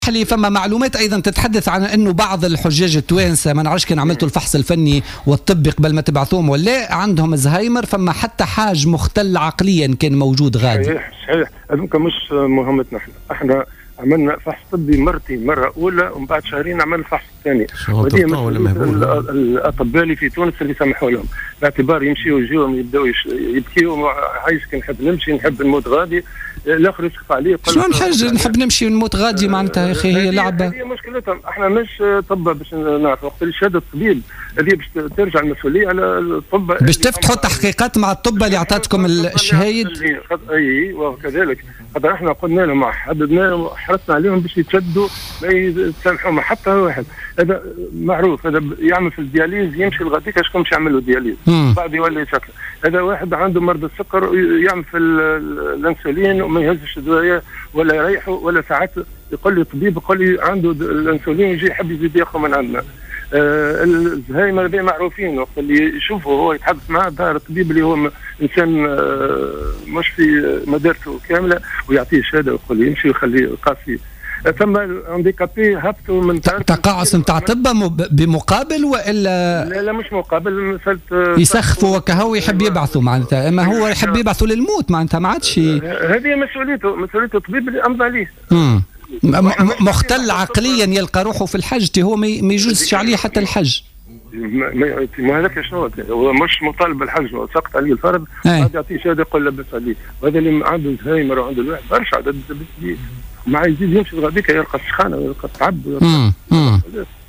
وأكد بطيخ خلال مداخلة له في برنامج "بوليتيكا" أنه سيتم فتح تحقيق مع الأطباء الذين قدّموا لهم شهادات طبية تثبت قدرتهم على أداء الحج في حين أنه ليس باستطاعتهم ذلك.